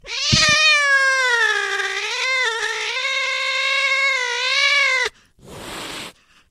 black_cat.ogg